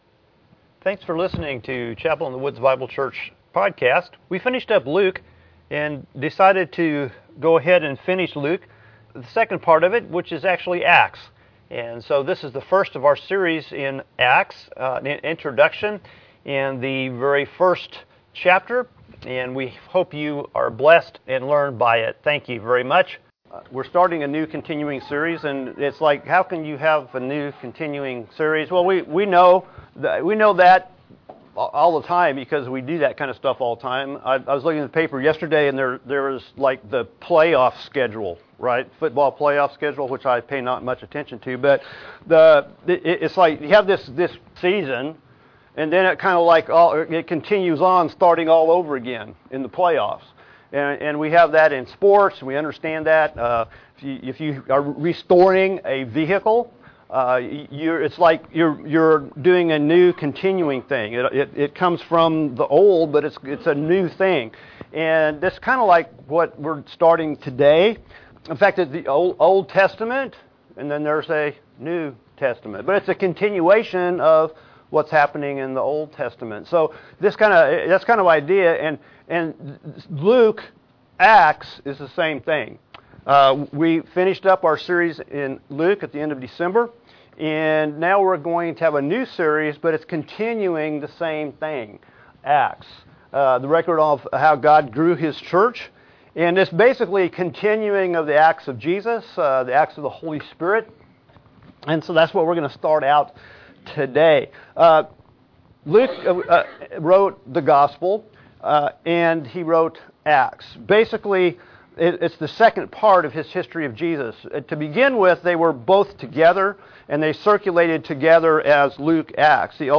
Jan 14, 2018 Acts 1 The Coming Power and Mission MP3 SUBSCRIBE on iTunes(Podcast) Notes Discussion Sermons in this Series The first of our Series in Acts, The Continuing Story of Jesus.